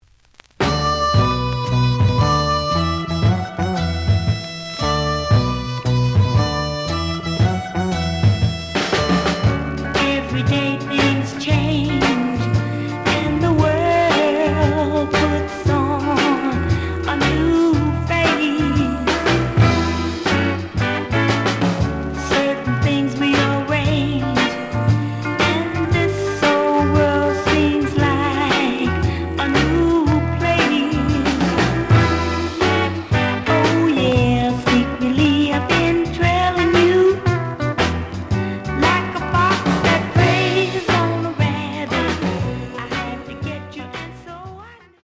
The audio sample is recorded from the actual item.
●Genre: Soul, 60's Soul
Slight sound cracking on A side.)